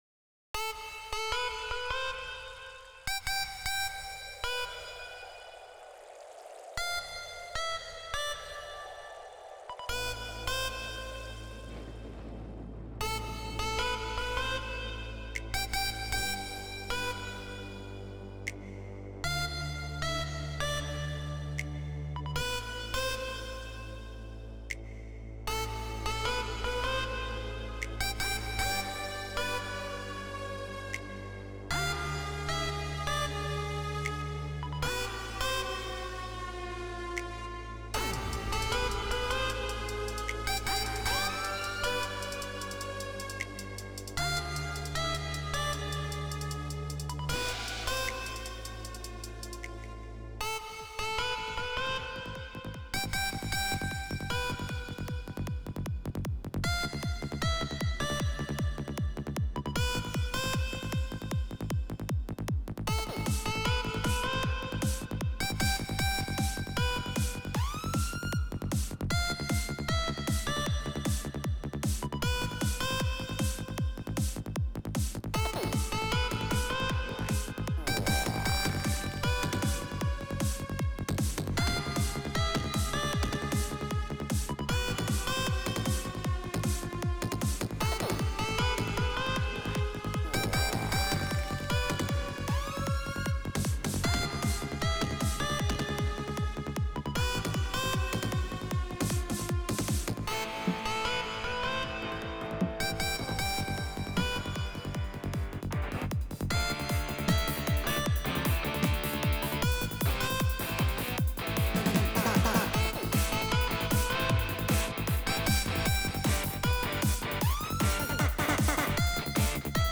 שילוב מענין של רוק ודאנס
סיגנון שלא שומעים כול כך באורגן